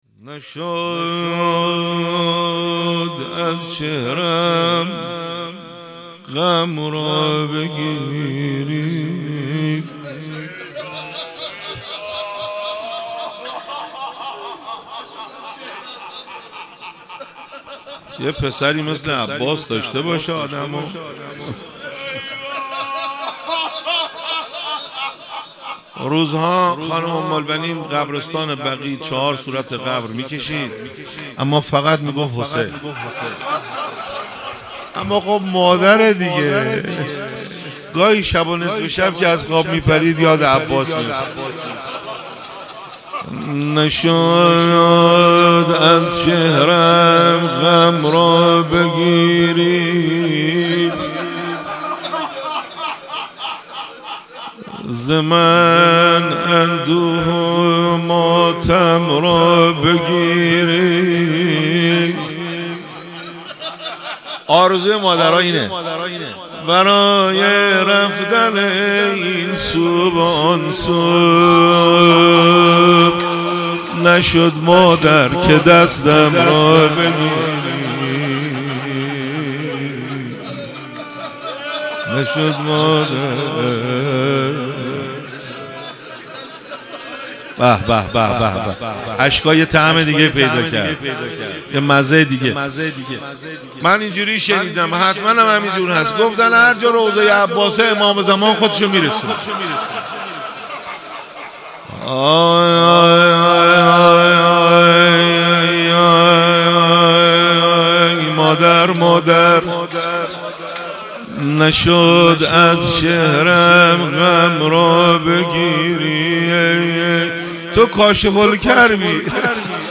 📥 روضه